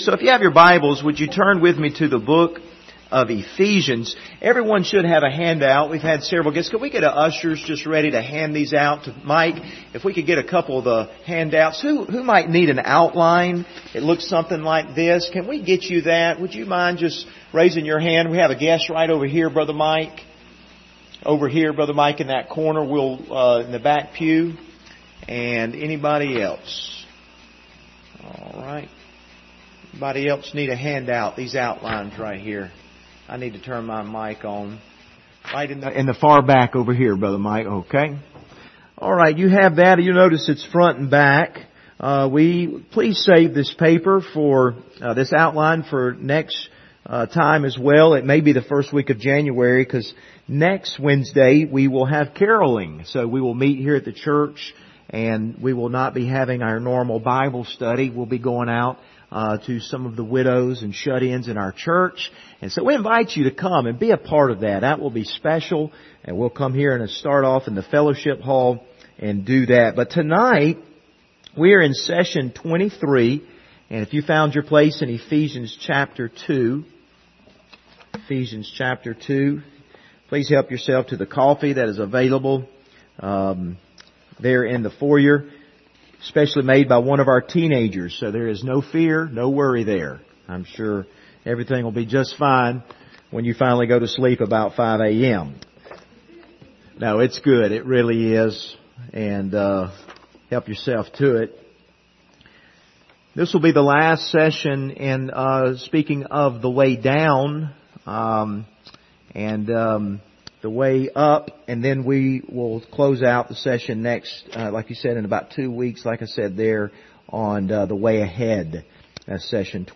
Ephesians 2:1-3 Service Type: Wednesday Evening « The Lord is My Shepherd Lord